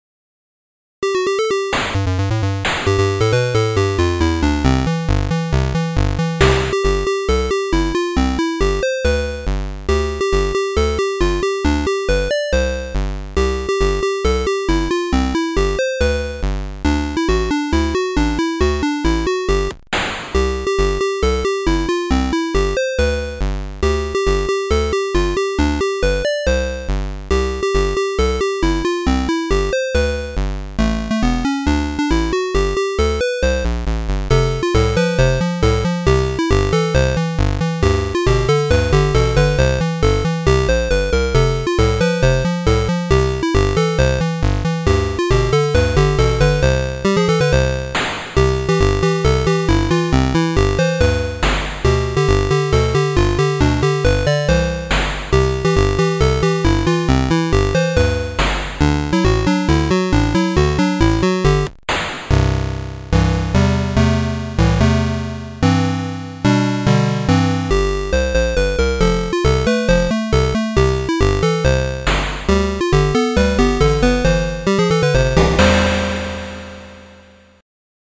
"POLKA"
Atari-ST Emulation